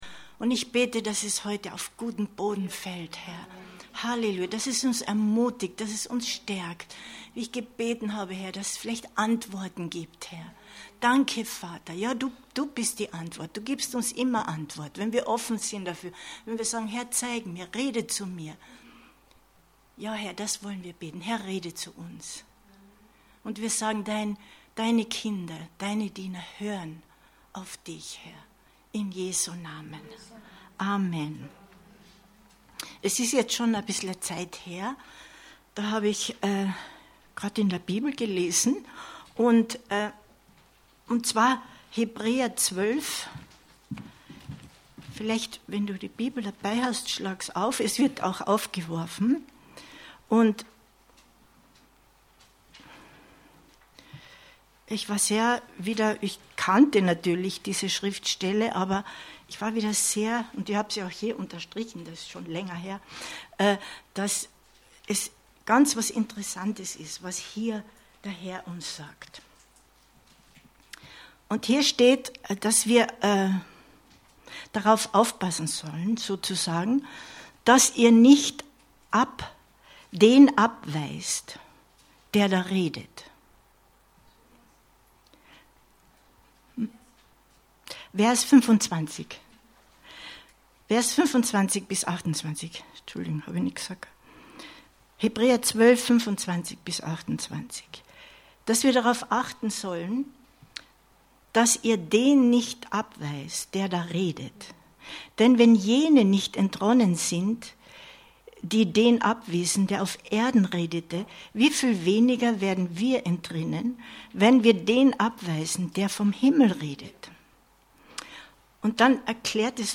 Das unerschütterliche Fundament Gottes 10.10.2021 Predigt herunterladen